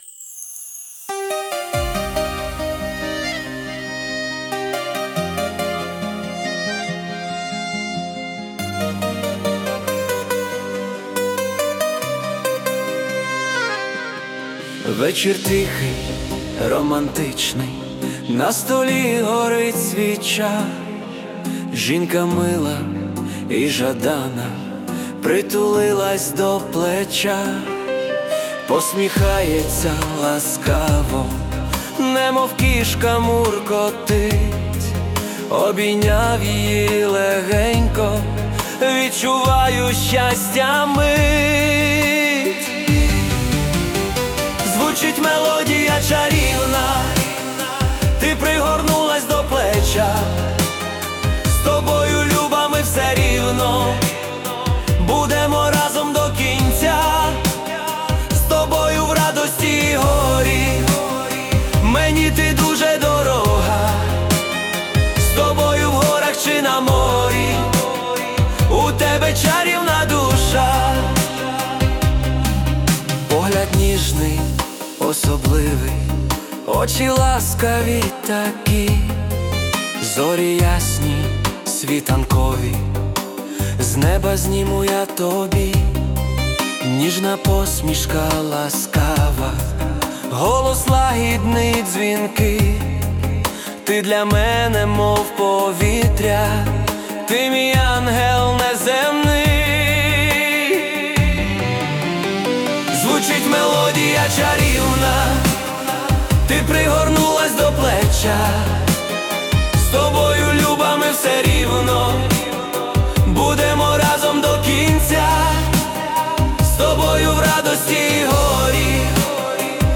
СТИЛЬОВІ ЖАНРИ: Ліричний